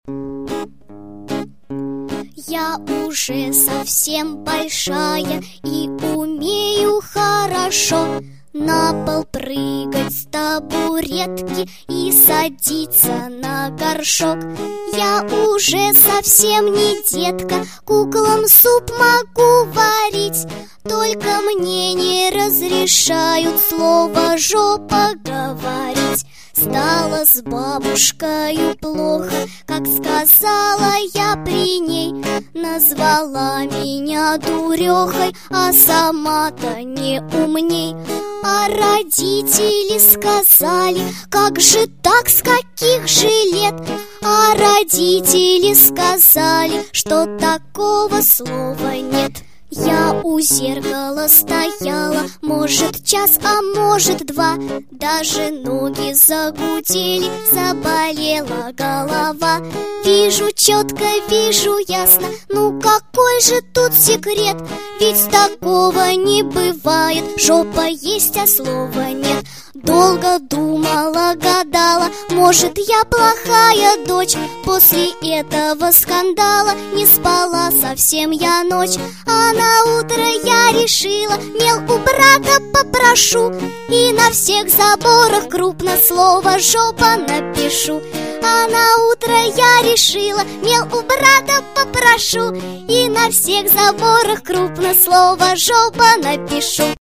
И в подарок детская песенка